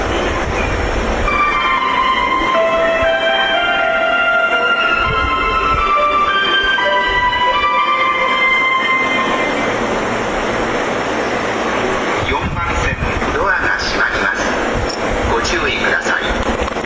曲名不明 サウンドファクトリー製メロディ